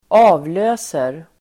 Uttal: [²'a:vlö:ser]